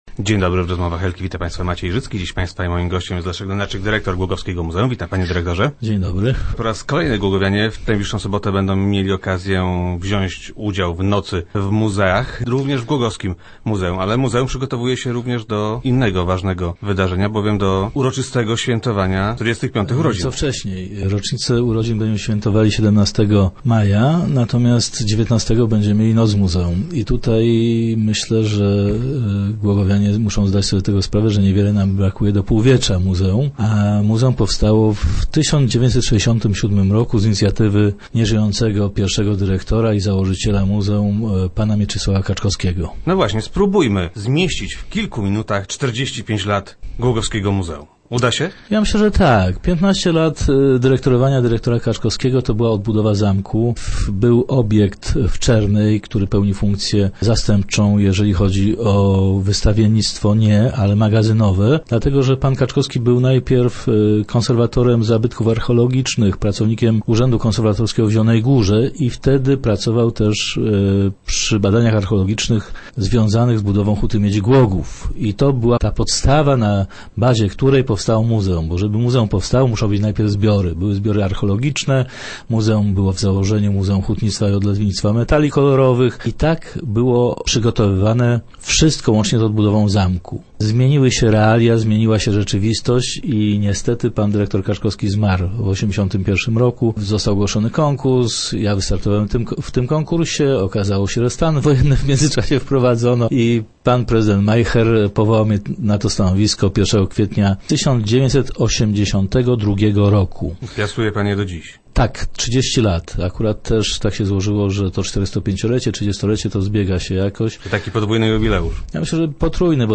W 1984 muzeum stało się jednostką wojewódzką.